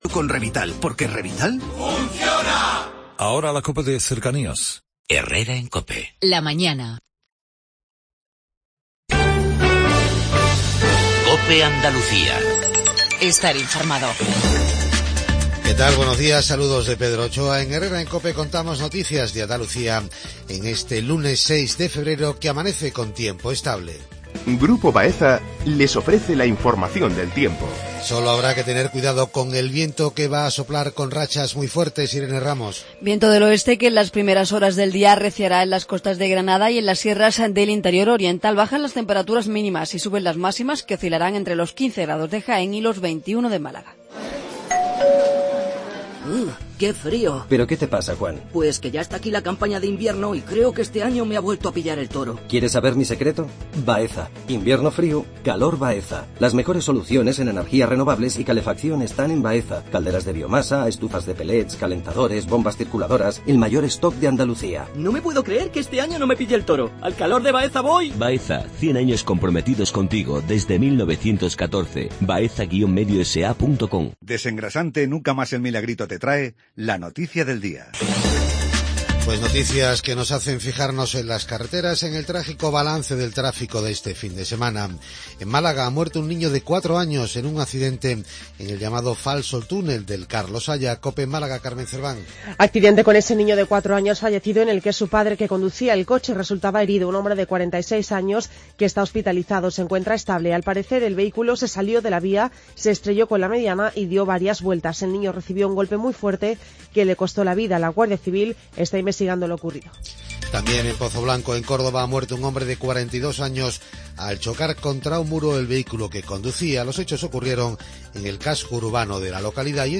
INFORMATIVO REGIONAL/LOCAL MATINAL